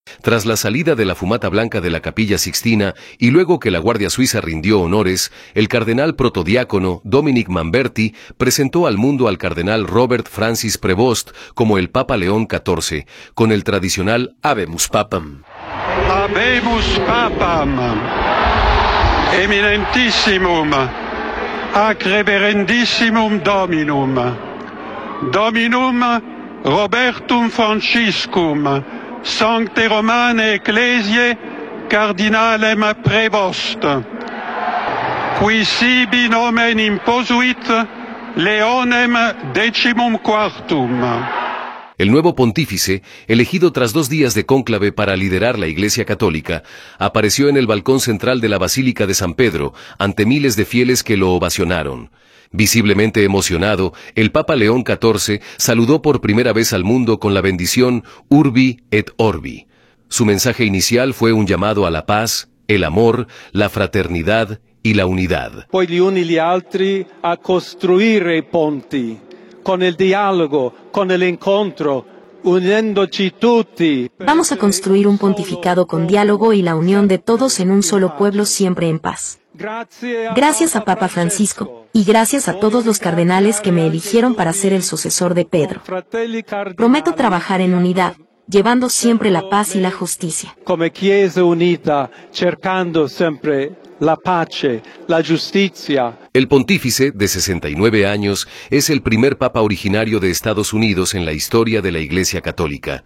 El nuevo Pontífice, elegido tras dos días de cónclave para liderar la Iglesia Católica, apareció en el balcón central de la Basílica de San Pedro ante miles de fieles que lo ovacionaron. Visiblemente emocionado, el Papa León XIV saludó por primera vez al mundo con la bendición Urbi et Orbi. Su mensaje inicial fue un llamado a la paz, el amor, la fraternidad y la unidad.